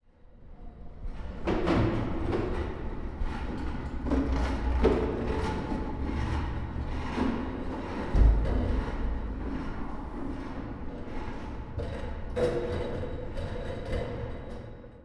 Soundscape Overhaul / gamedata / sounds / ambient / soundscape / underground / under_1.ogg